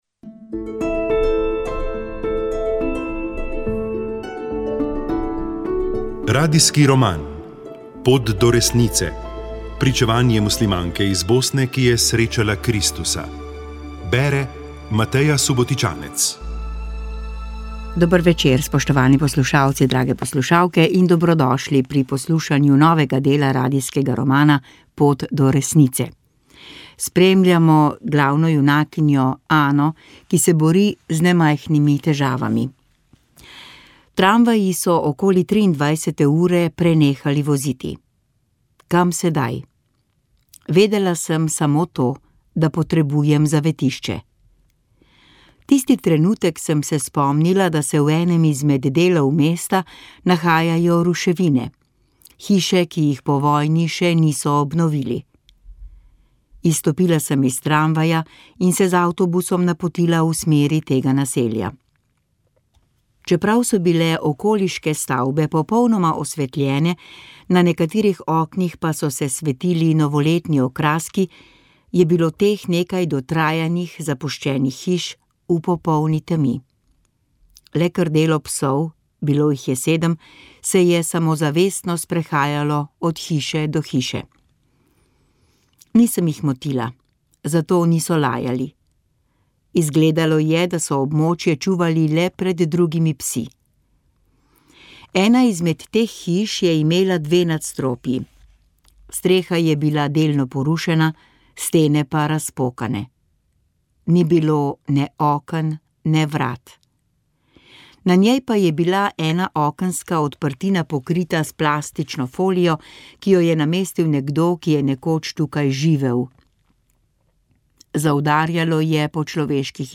Prisluhnili ste komentarju aktualnega političnega dogajanja.